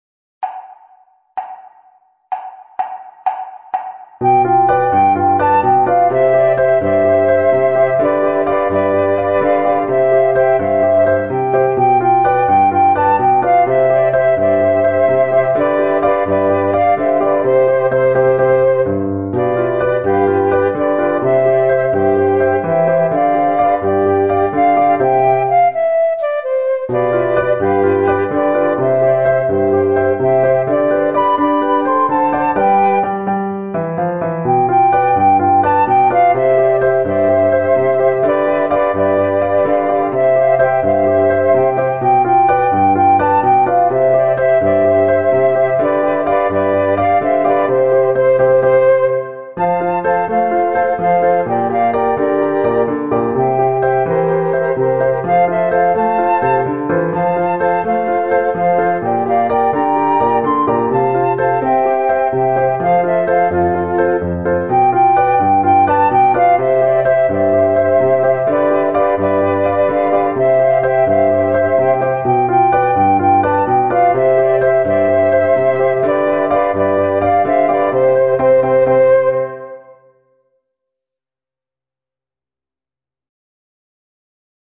Voicing: Flute Ensemble